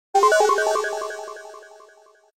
• Категория: Исчезновение, пропадание
• Качество: Высокое